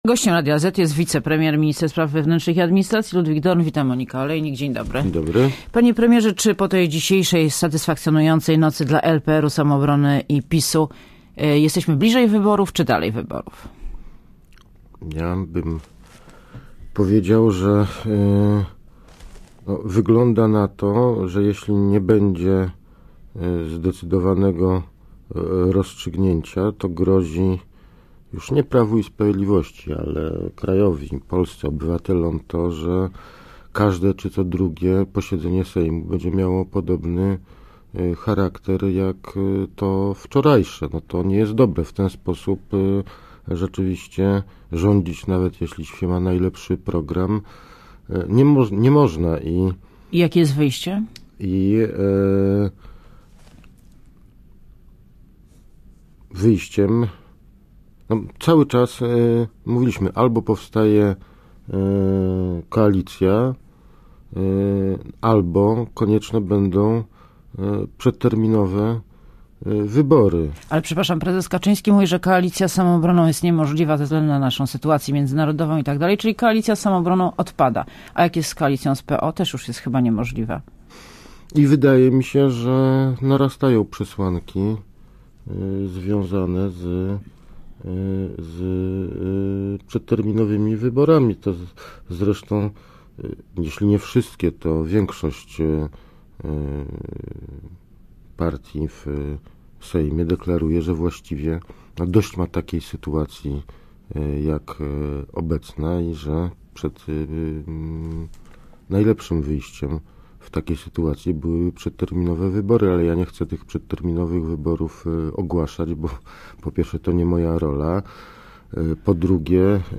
Posłuchaj wywiadu pytanie> Gościem Radia ZET jest wicepremier i minister spraw wewnętrznych i administracji, Ludwik Dorn.